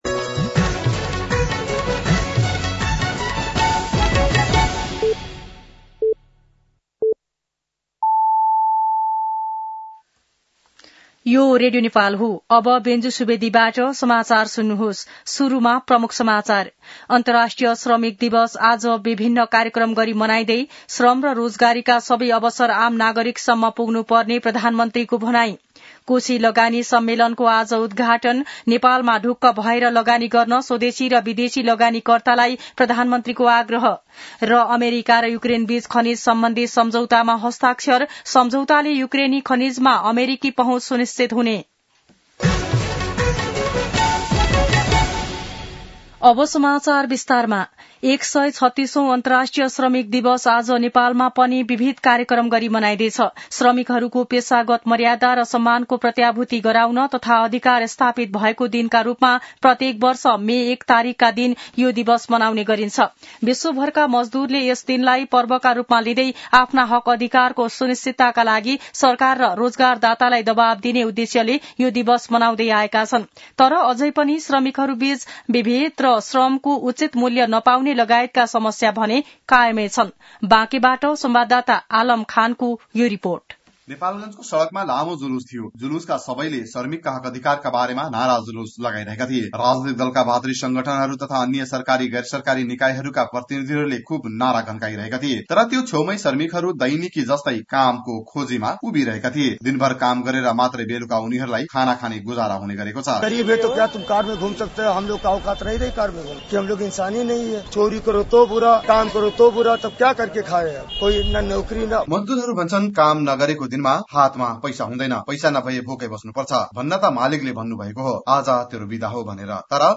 दिउँसो ३ बजेको नेपाली समाचार : १८ वैशाख , २०८२
3pm-News-01-18.mp3